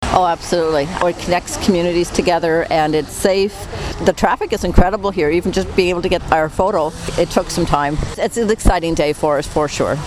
Wednesday afternoon’s ceremony drew dignitaries from both Renfrew County and the neighbouring communities of McNab-Braeside and Arnprior to site on Madawaska Boulevard.
Warden Robinson says the job was well worth the 1.5 million dollar price tag.
dec-9-debbie-robinson-bridge-opening-3.mp3